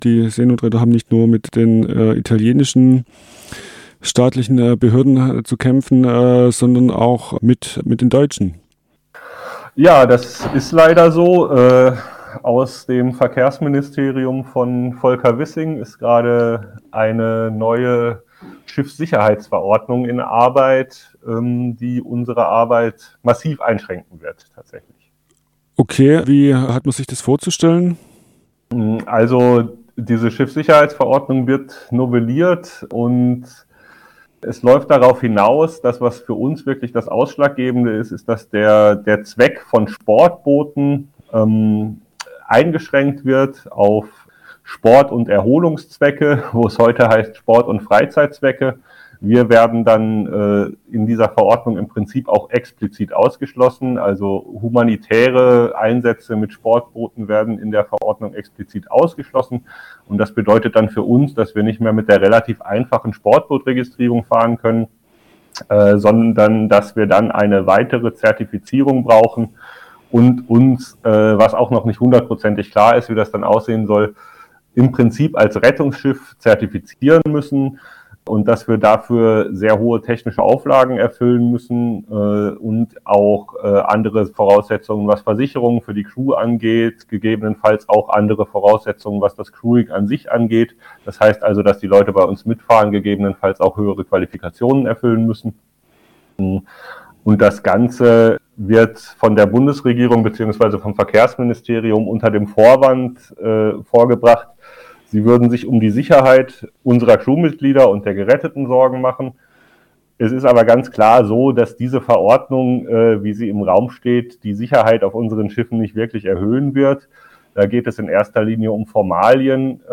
Interview Teil 2